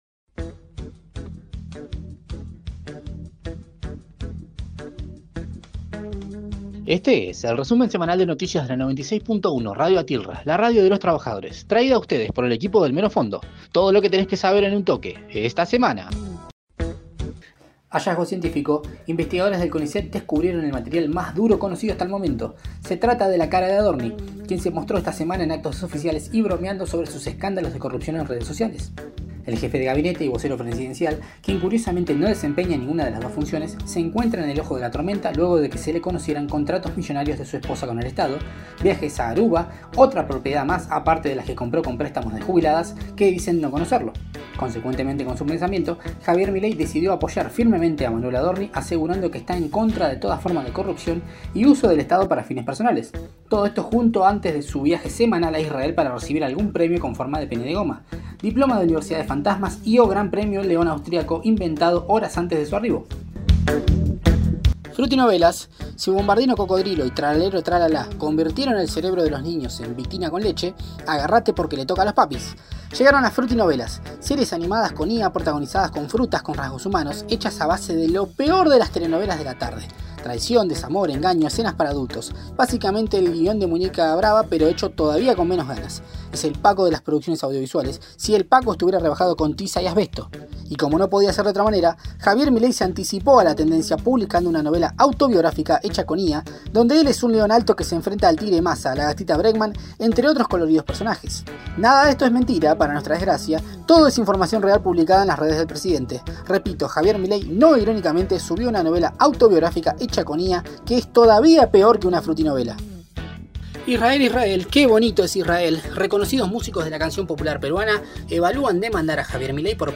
Flash Informativo